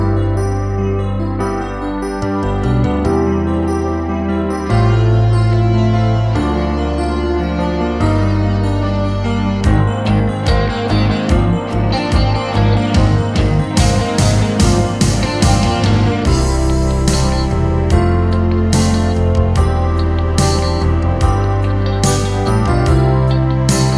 Minus All Guitars Pop (1980s) 4:20 Buy £1.50